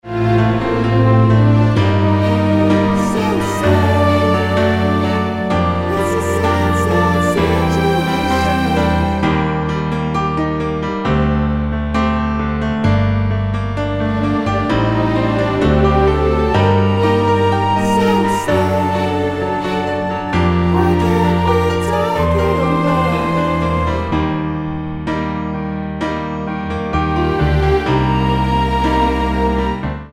Tonart:Gm mit Chor
Die besten Playbacks Instrumentals und Karaoke Versionen .